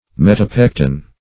Search Result for " metapectin" : The Collaborative International Dictionary of English v.0.48: Metapectin \Met`a*pec"tin\, n. (Chem.) A substance obtained from, and resembling, pectin, and occurring in overripe fruits.
metapectin.mp3